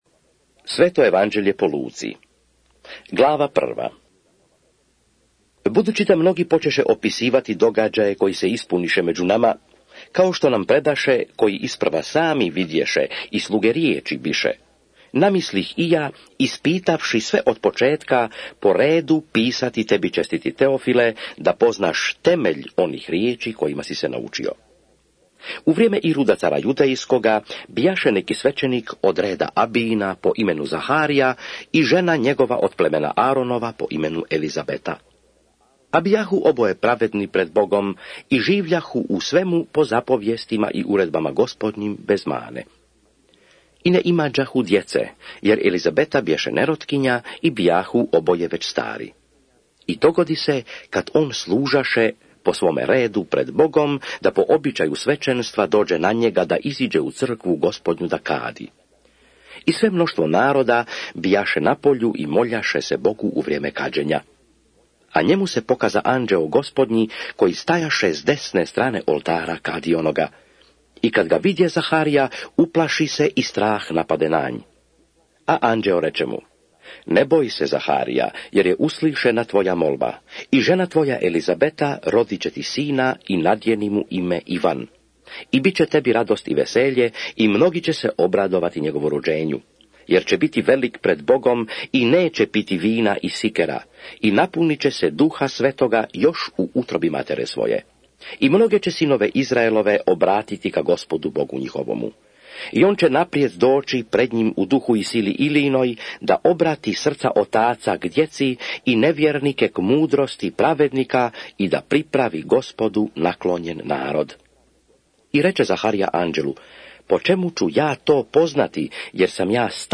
LUKA(ČITANJE) - Bible expounded
SVETO PISMO – ČITANJE – Audio mp3 LUKA